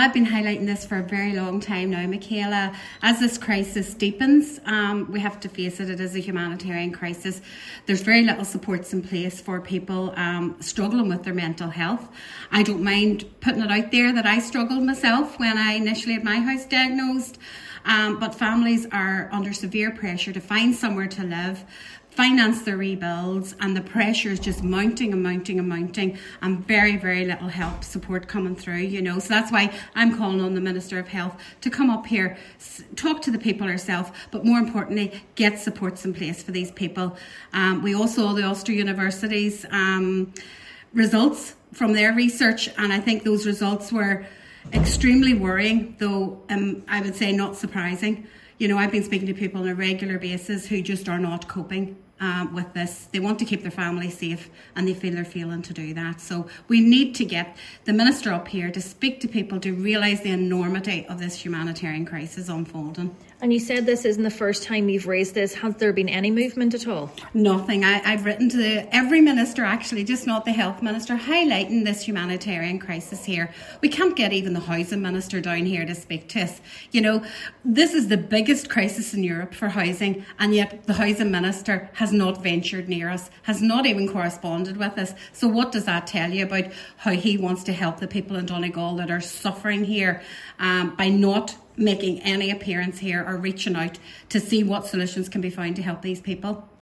She says while people’s homes are crumbling around them, so too is their health: